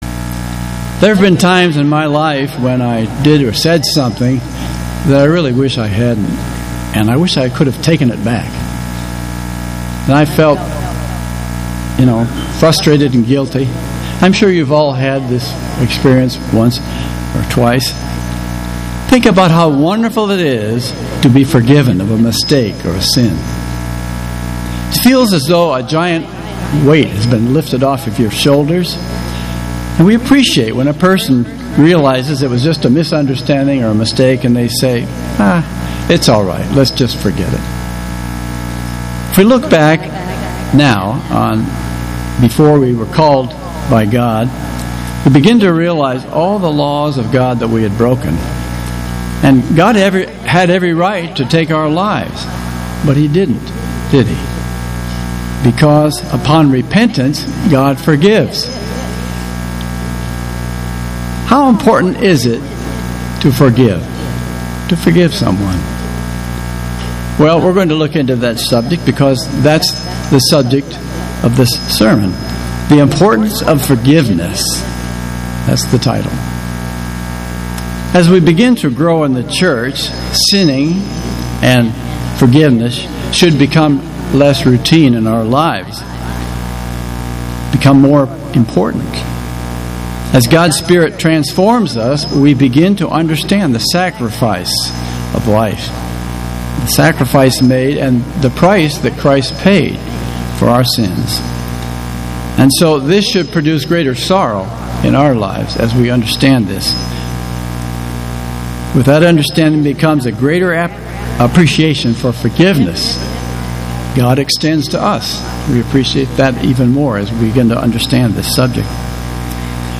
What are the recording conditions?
Given in Atlanta, GA